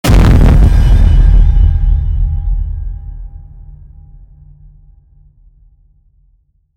Âm thanh một tiếng Bass mạnh căng thẳng
Thể loại: Hiệu ứng âm thanh
Description: Âm thanh một tiếng Bass mạnh căng thẳng là hiệu ứng âm thanh gợi cho người nghe cảm giác căng thẳng, hồi hộp như dự cảm những điều lớn lao sắp xảy ra, hiệu ứng tiếng bass đột ngột xuất hiện như cảnh báo người nghe những điềm chẳng lành, âm thanh khiến người nghe như run rẩy và chuẩn bị tâm lý cho những điều tồi tệ sắp xảy đến.
Am-thanh-mot-tieng-bass-manh-cang-thang-www_tiengdong_com.mp3